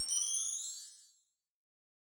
Boss Dr Pad Sample Pack_Chime-Short.wav